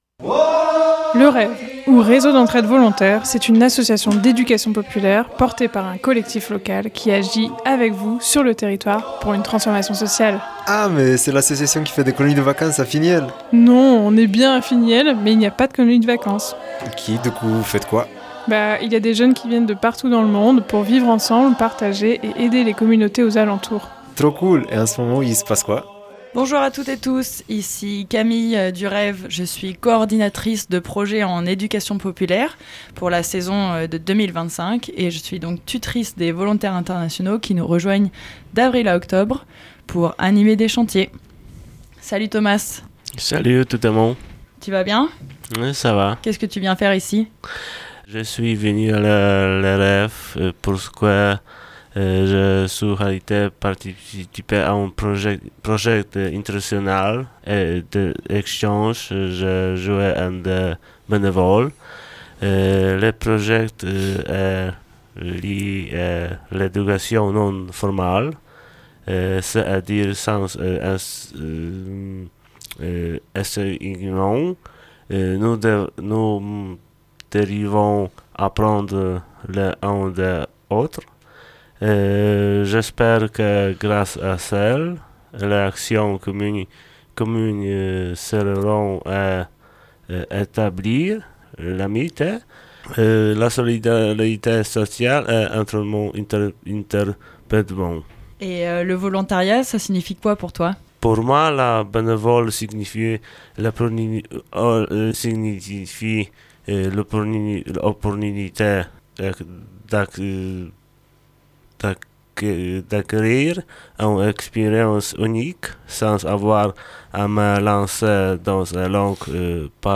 Les volontaires du REV se présentent à vous dans ce nouvel épisode.
C’est une émission animée par les membres de l’association REV (pour Réseau d’Entraide Volontaire), consacrée à l’interculturalité, l’intergénérationnalité, au vivre ensemble et à l’émancipation individuelle par le collectif.